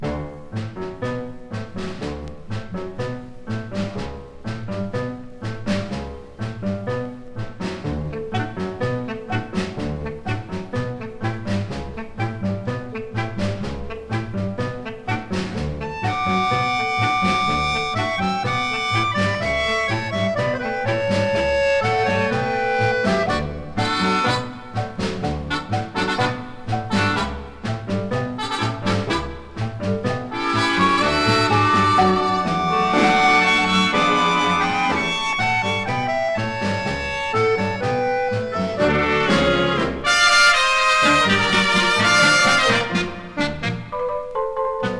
熱さもあり、涼やかさもあり、流暢でキレのある演奏はバンドの充実度が高く魅力いっぱいです。